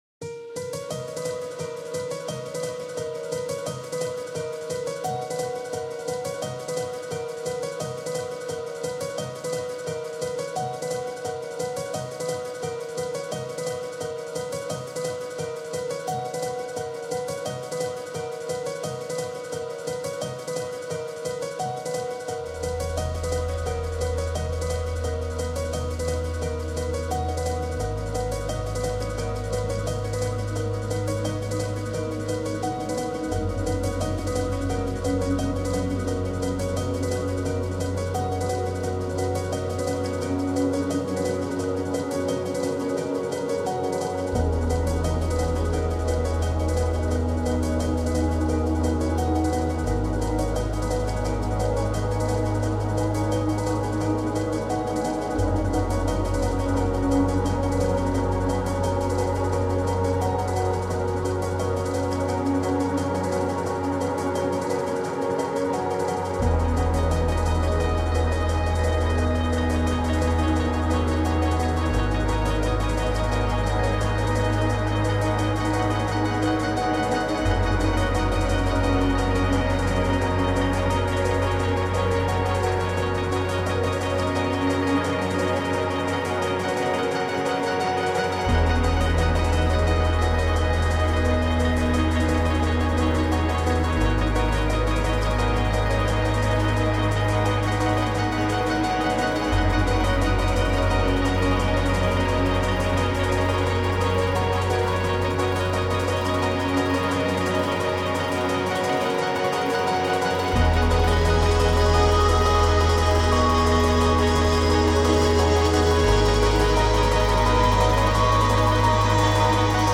موسیقی اینسترومنتال
موسیقی الکترونیک
electronic music